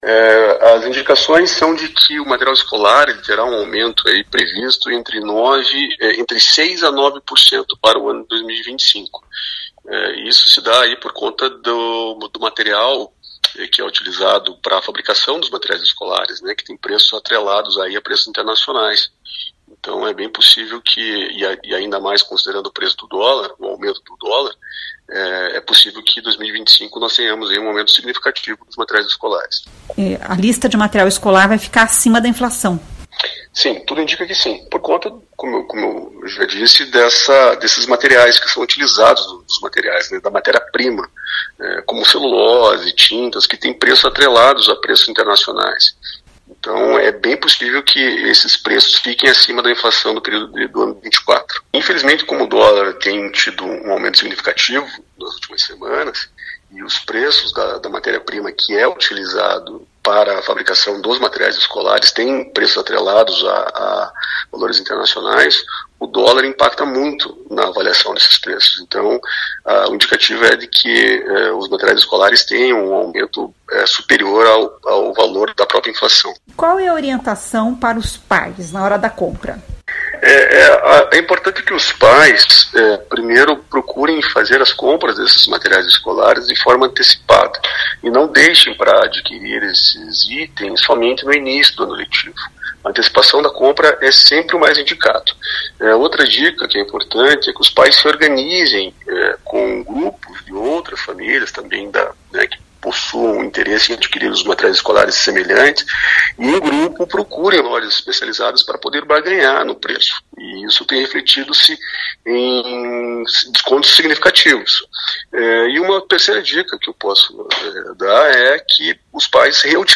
Ouça o que diz o advogado